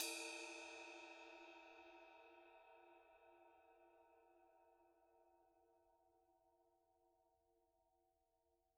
Percussion
susCymb1-hitstick_mp_rr2.wav